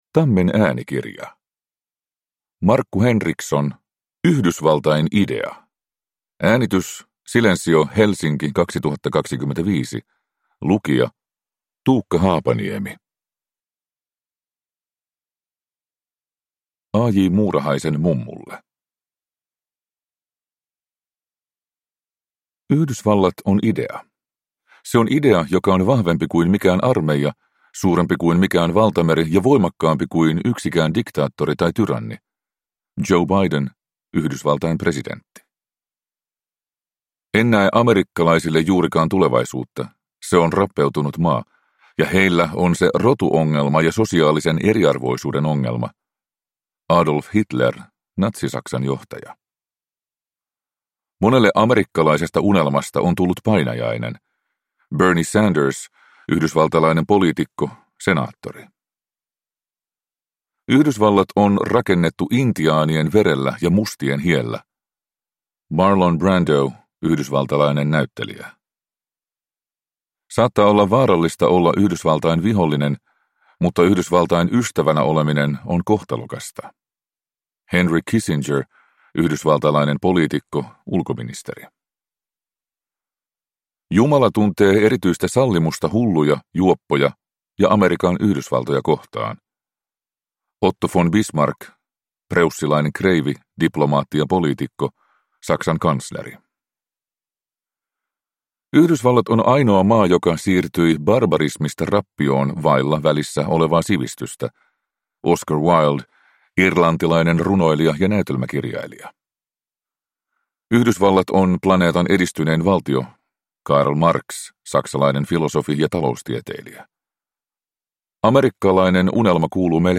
Yhdysvaltain idea – Ljudbok